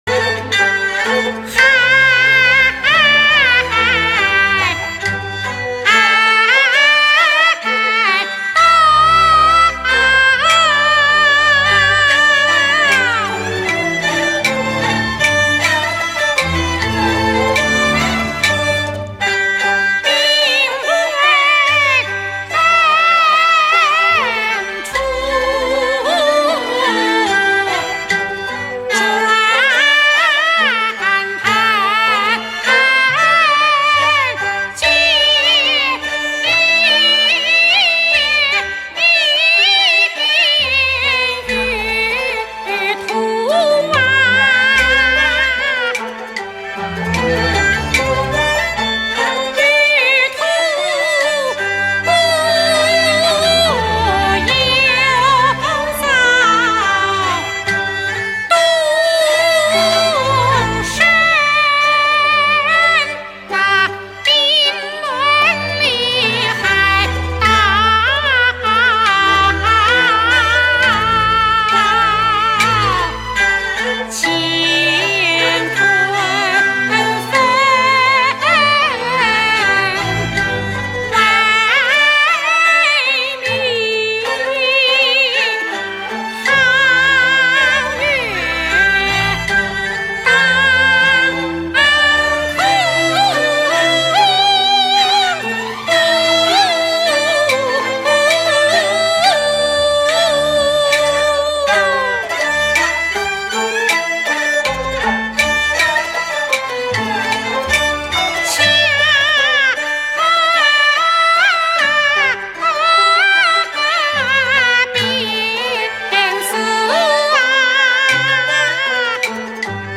From the Classic Peking Opera, Drunken Concubine